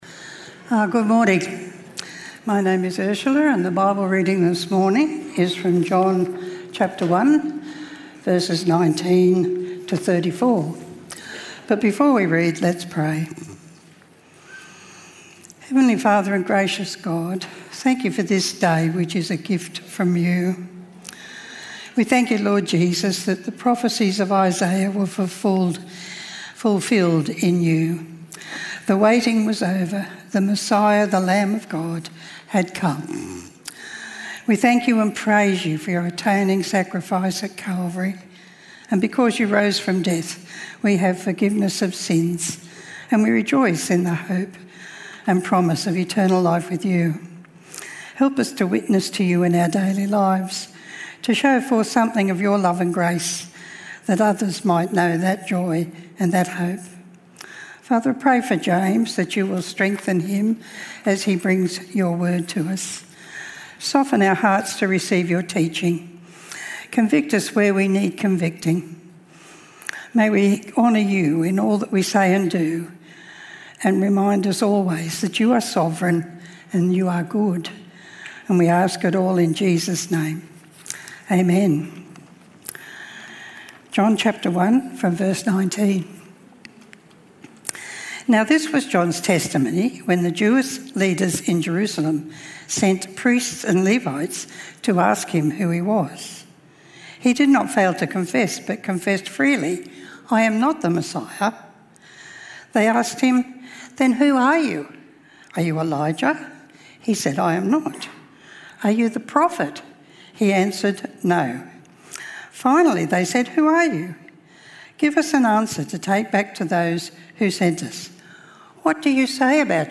Talk-JesusTheOneTheyWereWaitingFor.mp3